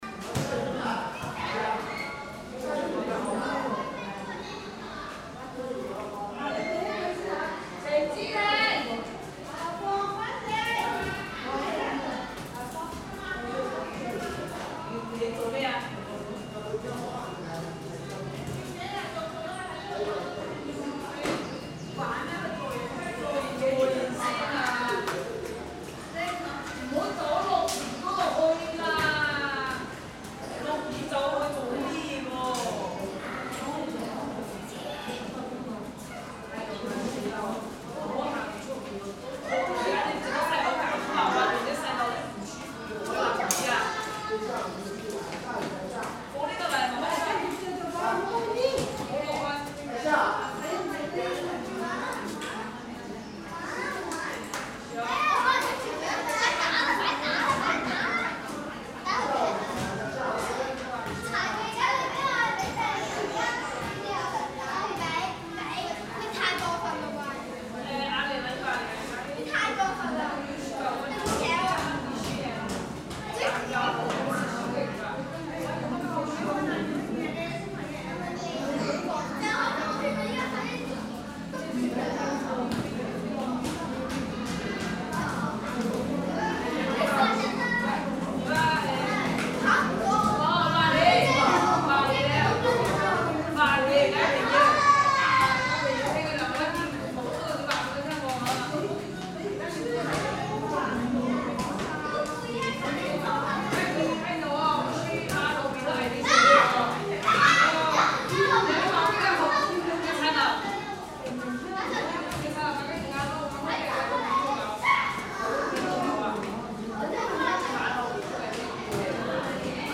KL0023 土瓜灣樂民新村樂鄰樓有蓋公共空間 To Kwa Wan Lok Man Sun Chuen Lok Lun Lau Covered Common Area
Tags Basketball , Chatting , Kids , Shouting , Table Tennis , 乒乓波 , 大叫 , 小孩 , 籃球 , 聊天
Lok Man Sun Chuen is named after the former Lok Man Village Resettlement Area built on part of its original site after the war, making it the first Class A rental housing estate self-financed by the Hong Kong Housing Society. The recording location is in the covered common area under Lok Lun Lau (Block F), where the space is filled with the sounds of children playing, shooting basketballs, and chasing each other, occasionally punctuated by joyful screams. Additionally, there are table tennis tables here, accompanied by the sound of paddles hitting the balls.
錄音器材 Recording Device: Tascam Portacapture X8 w/ Clippy EM272
錄音位置是在樂鄰樓（F 座）下的有蓋公共空間，整個區域充滿了小孩嬉戲、打籃球和追逐的聲音，偶爾發出歡快的尖叫聲；同時，這裡亦設有乒乓波枱，伴隨著球拍擊球的聲音。